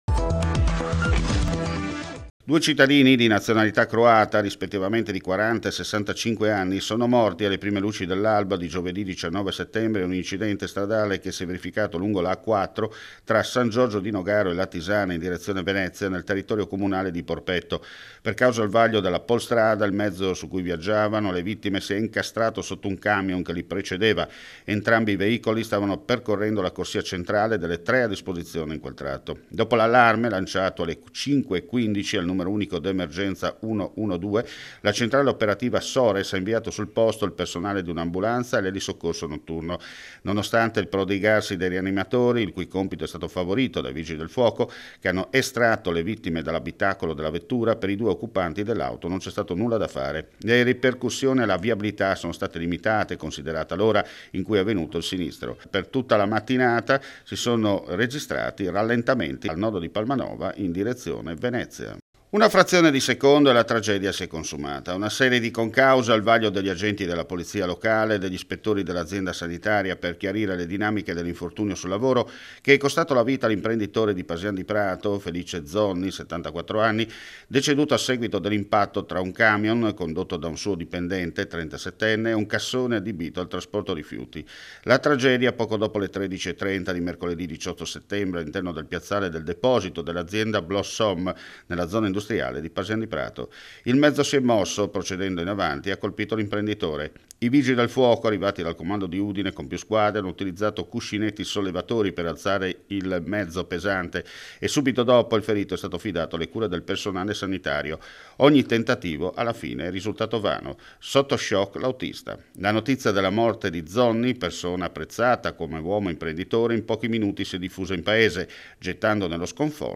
FRIULITV GIORNALE RADIO: LE ULTIME AUDIONOTIZIE DAL FVG
PARTE IL SERVIZIO DI CONTROLLO DA PARTE DELLE GUARDIE GIURATE SUI BUS CITTADINI. AL NOSTRO MICROFONO ELENA CEOLIN ASSESSORE COMUNALE ALLA SICUREZZA.
AL NOSTRO MICROFONO IL PREFETTO DI UDINE DOMENICO LIONE.